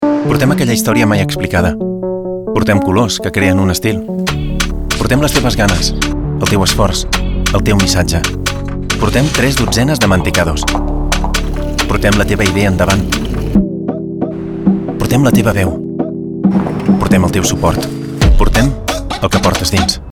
Naturel
Chaleureux
Fiable